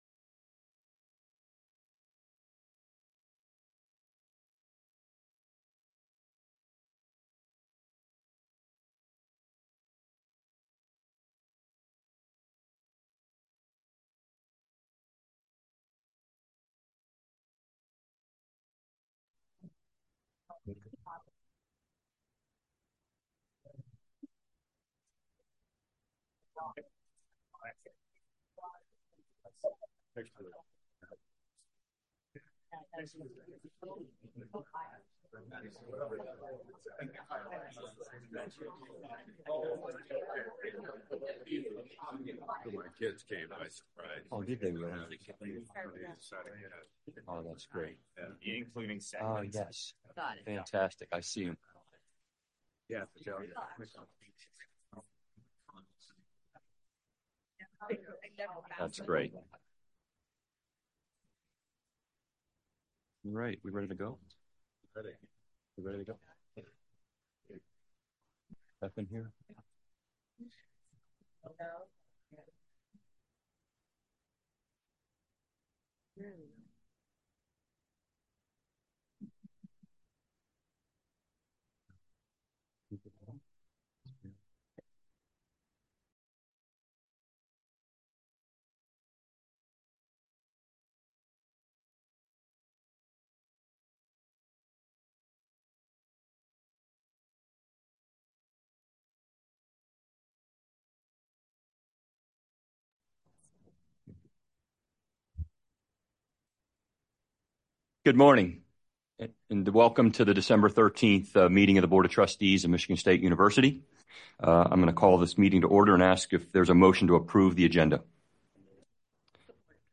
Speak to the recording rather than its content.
Where: Board Room, 401 Hannah Administration Building